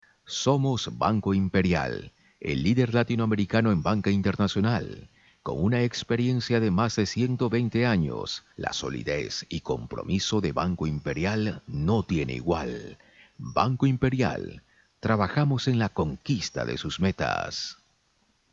spanisch Südamerika
Sprechprobe: Werbung (Muttersprache):